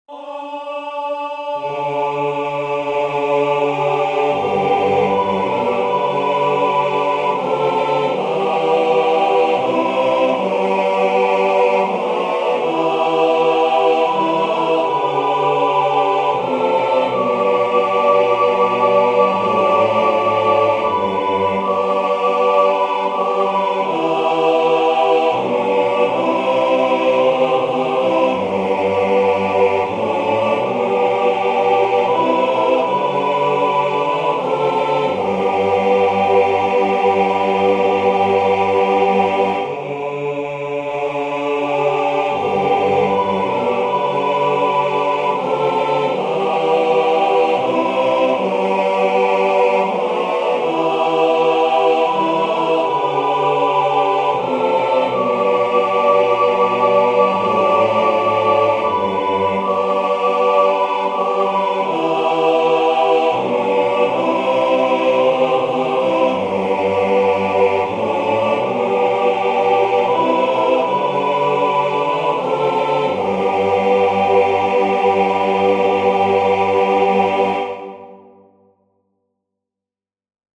Accompaniment (Copyright)